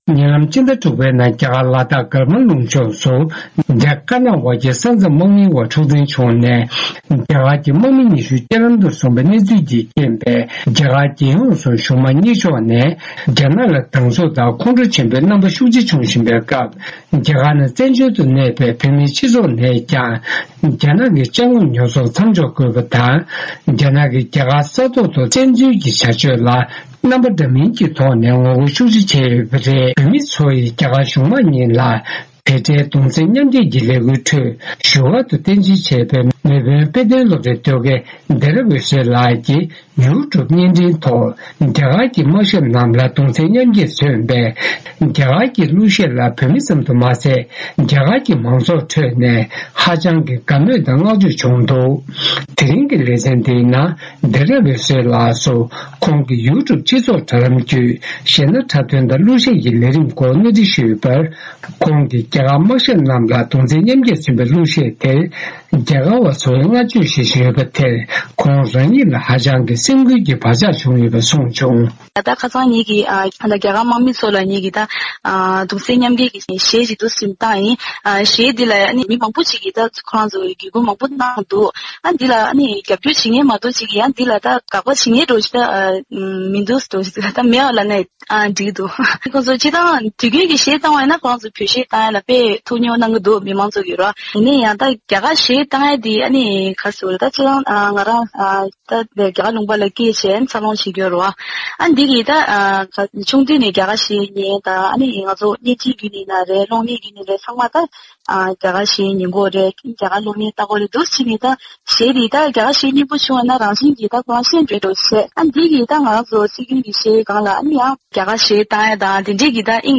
བཅར་འདྲི།